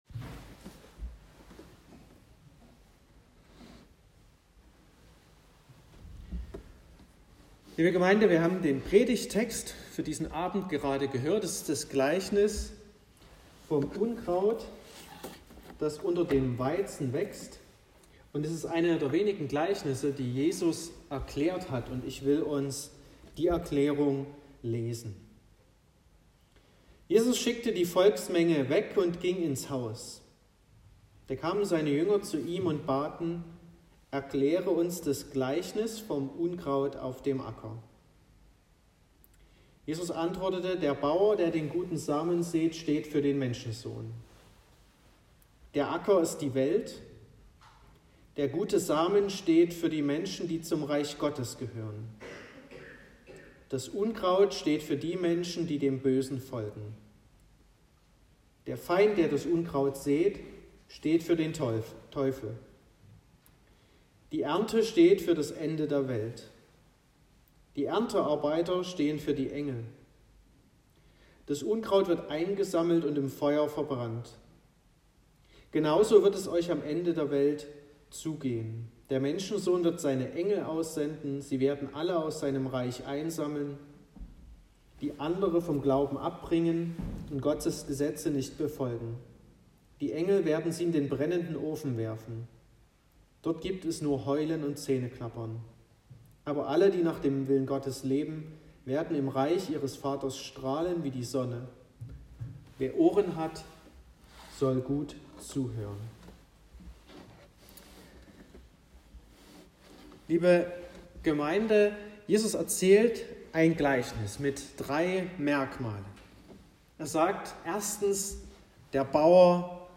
31.12.2021 – Gottesdienst
Predigt und Aufzeichnungen